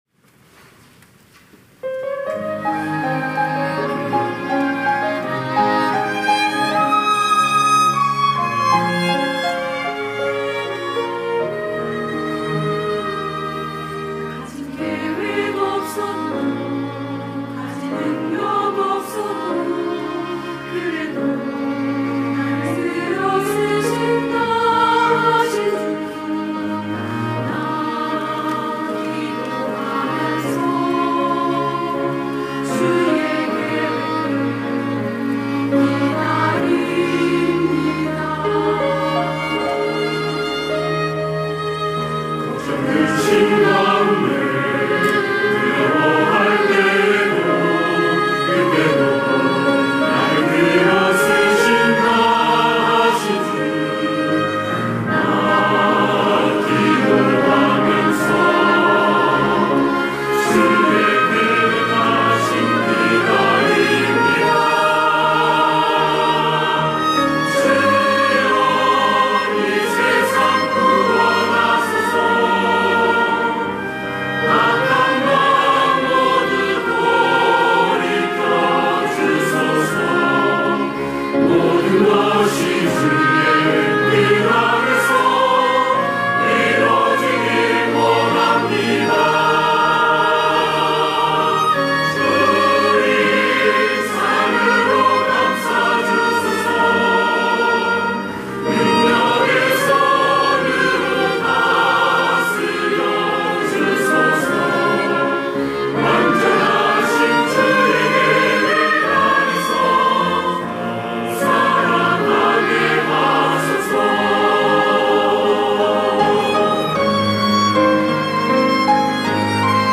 할렐루야(주일2부) - 노아의 기도
찬양대 할렐루야